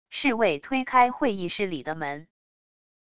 侍卫推开会议室里的门wav音频